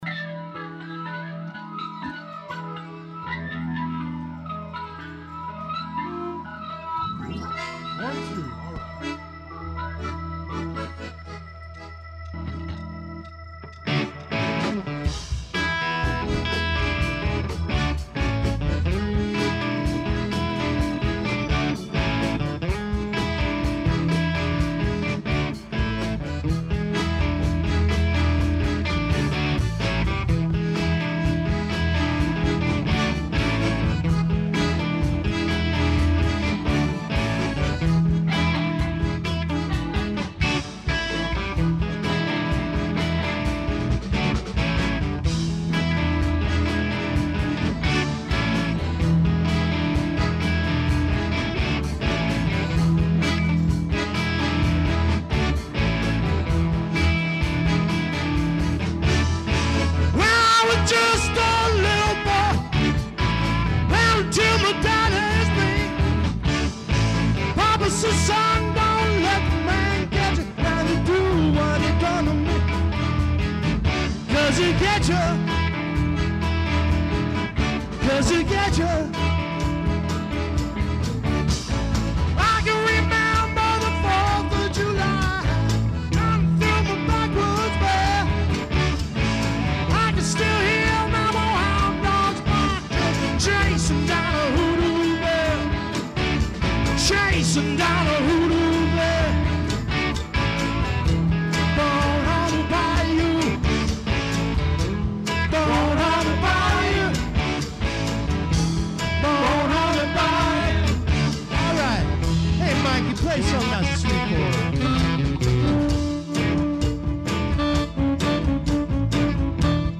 60s rock 'n' roll
country rock
zydeco
keyboards, squeezebox, zydeco rubboard
lead vocals, gtr
drums
vocals, rhythm gtr
bass